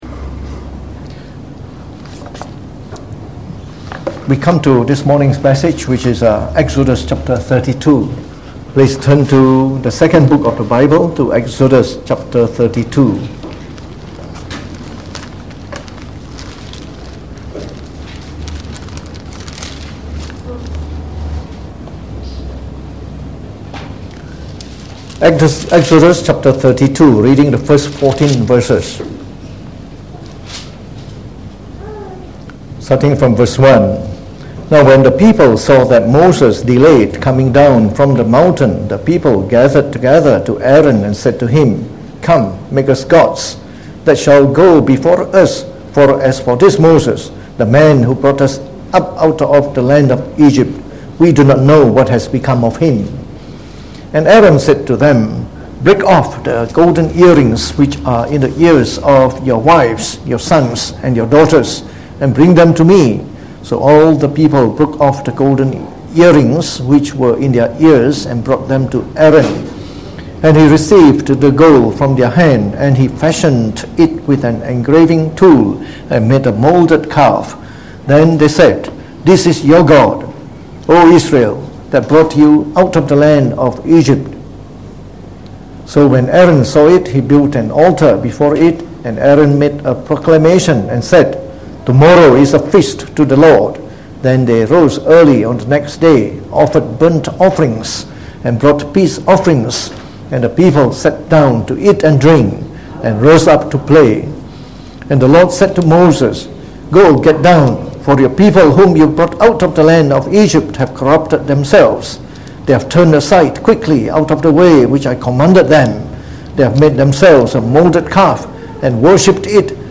Sermon
delivered in the Morning Service